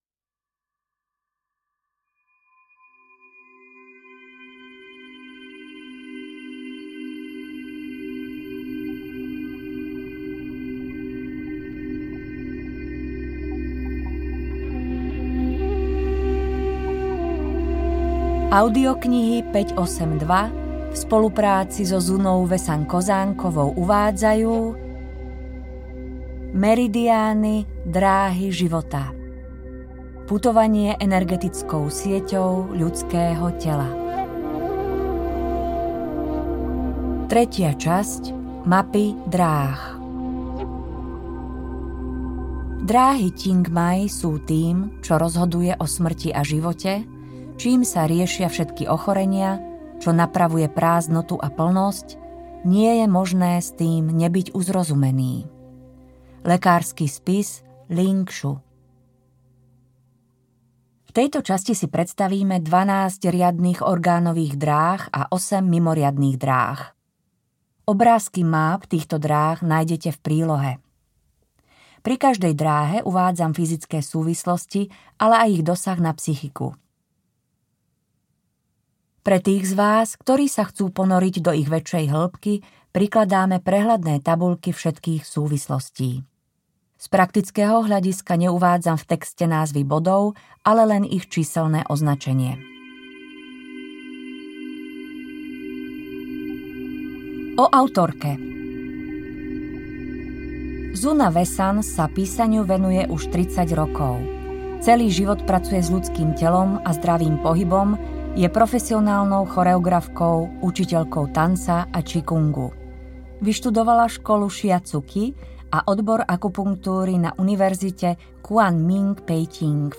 Meridiány – dráhy života audiokniha
Ukázka z knihy